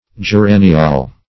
Search Result for " geraniol" : The Collaborative International Dictionary of English v.0.48: geraniol \ge*ra"ni*ol\, n. [See Geranium .]